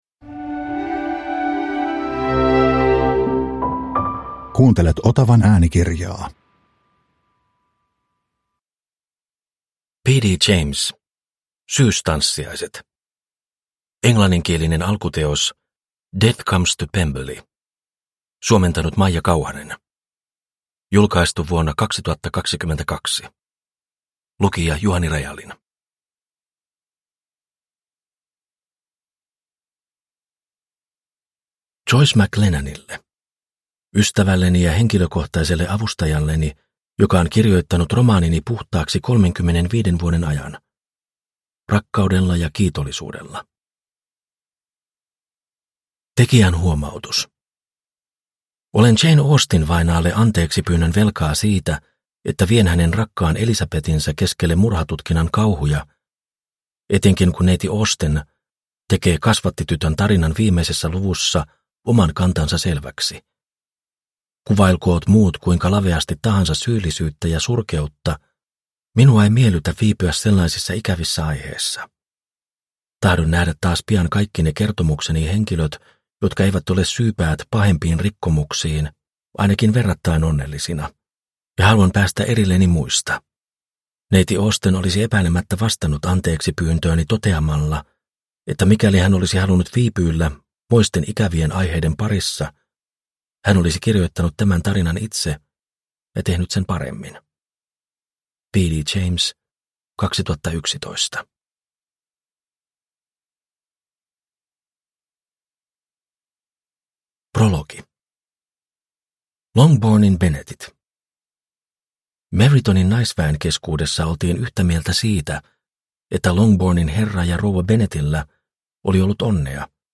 Syystanssiaiset – Ljudbok – Laddas ner